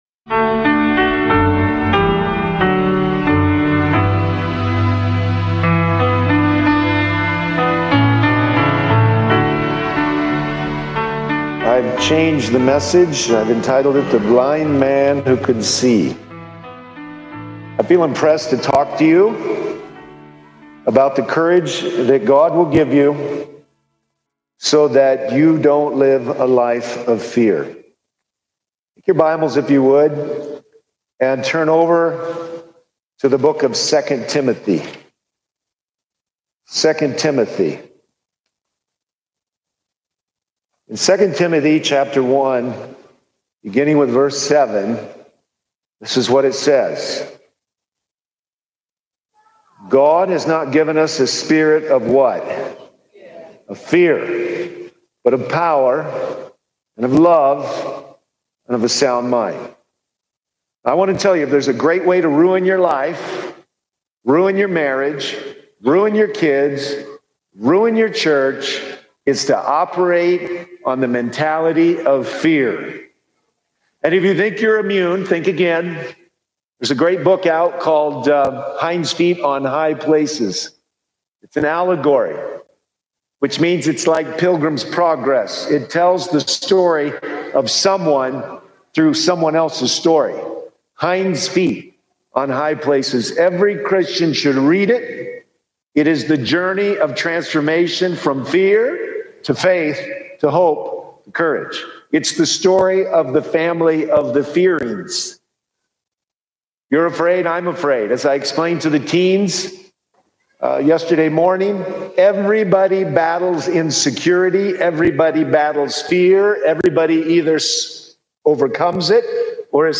This powerful sermon unpacks the battle between fear and faith, urging believers to live courageously through God’s strength, not human effort. From spiritual identity to practical obedience, it challenges Christians to reject passivity, embrace truth, and follow Jesus boldly—even when it costs.